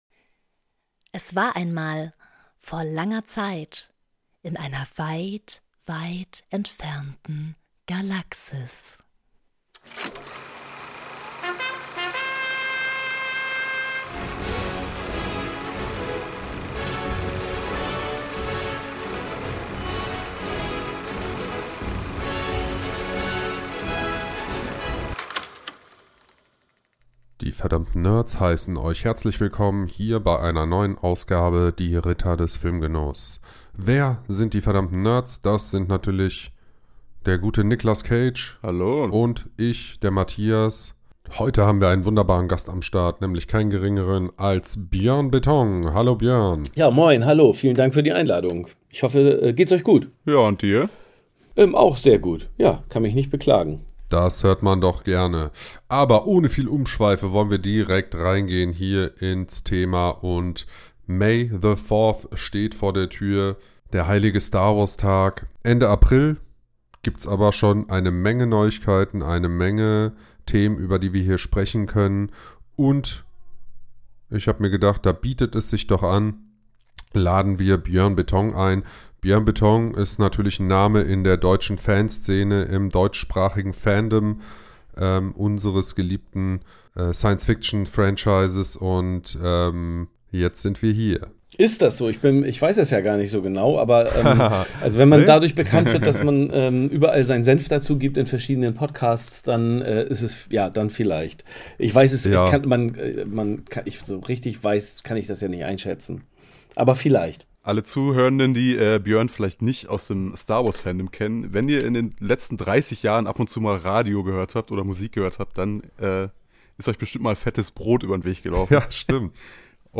Heute quatschen die Ritter über Star Wars und bekommen dabei Unterstützung von einem der bekanntesten Star-Wars-Fans Deutschlands: Musiker und Filmschaffender Björn Beton (Fettes Brot).